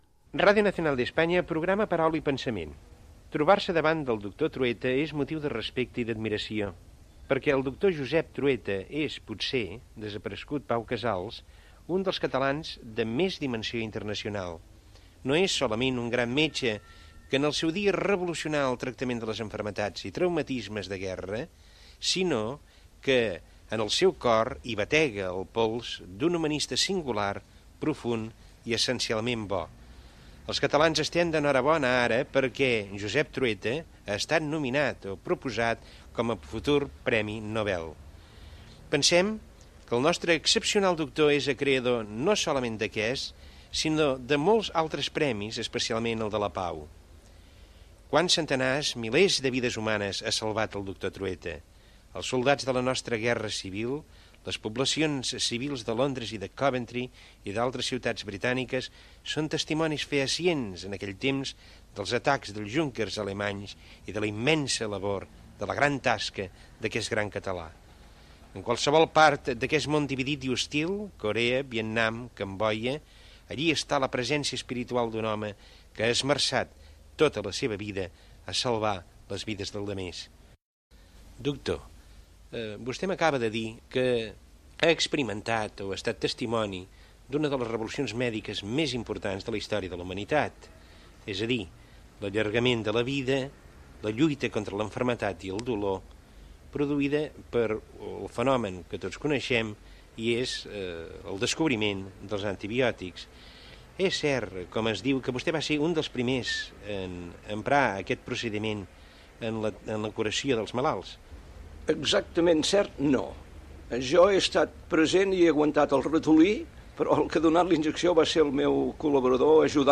Presentació i entrevista al doctor Josep Trueta. Parla de la seva feina com a metge atenent soldats en el desembarcament de Normandia durant la Segona Guerra Mundial i de la seva recerca mèdica